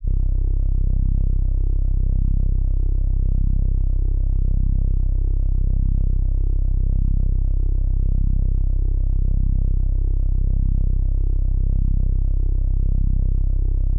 Basic Soft Pad_1.wav